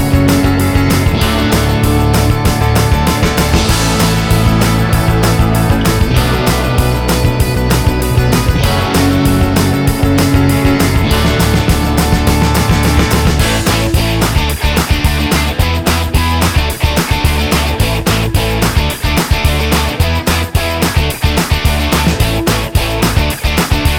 no Backing Vocals Soundtracks 2:03 Buy £1.50